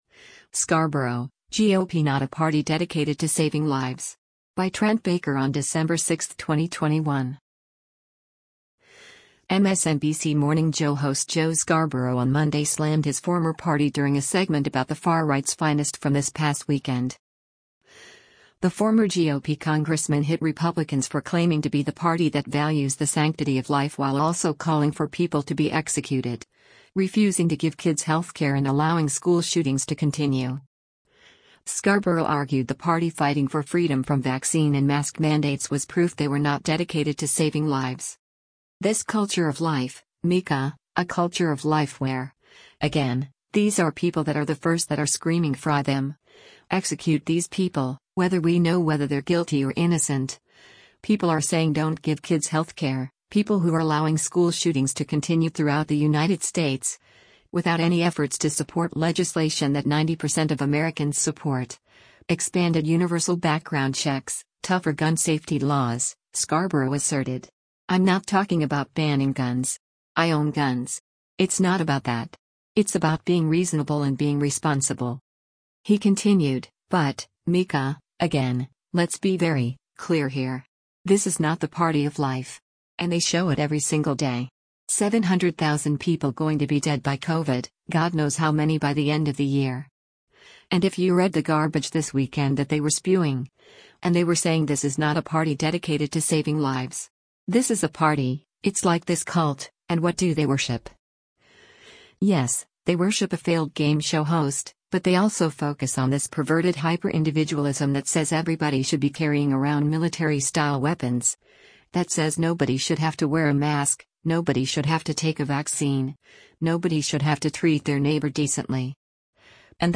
MSNBC “Morning Joe” host Joe Scarborough on Monday slammed his former party during a segment about “the far-right’s finest from this past weekend.”
“And the racism,” co-host Mika Brzezinski chimed in.